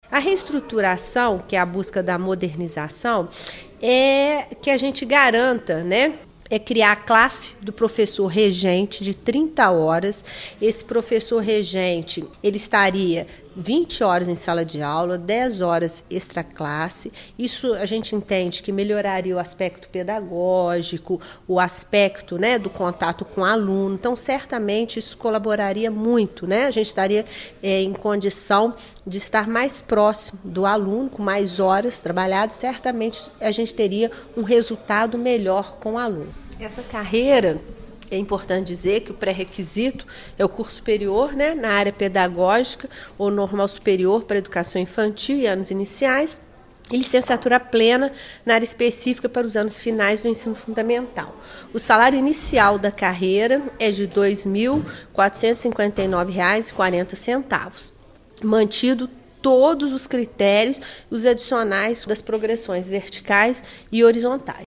Secretária de Administração e Recursos Humanos, Andréia Goreske